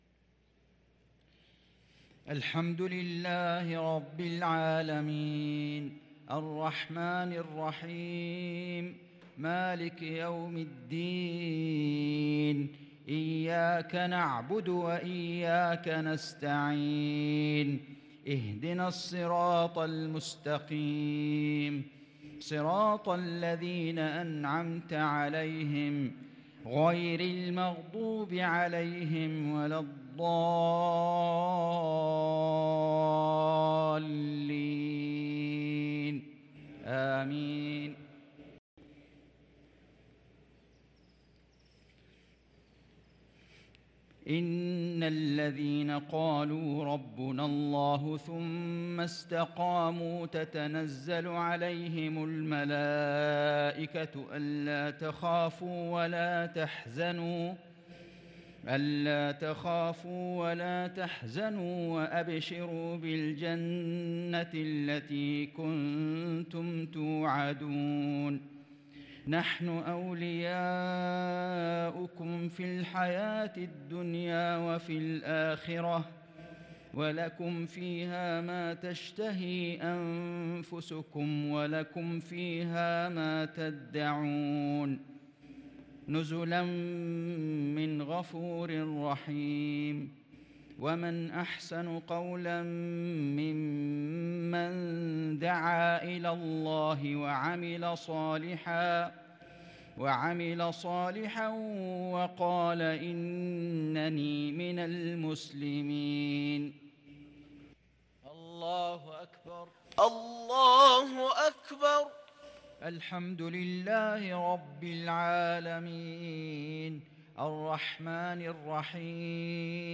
صلاة المغرب للقارئ فيصل غزاوي 3 ذو الحجة 1443 هـ
تِلَاوَات الْحَرَمَيْن .